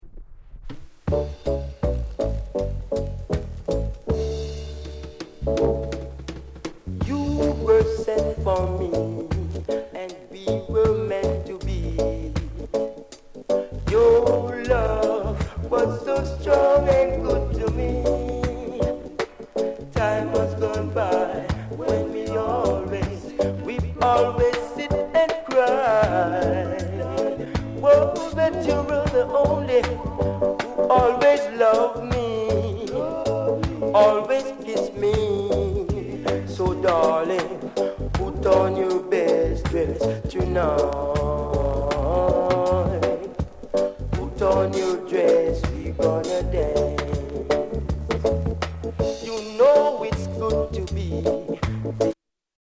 SOUND CONDITION VG